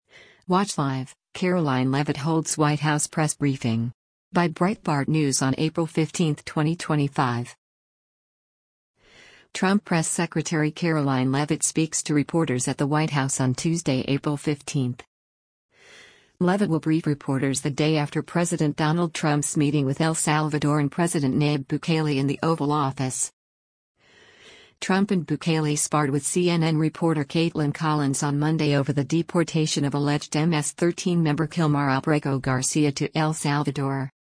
Trump Press Secretary Karoline Leavitt speaks to reporters at the White House on Tuesday, April 15.